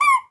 squeaky_rubber_toy_cartoon_05.wav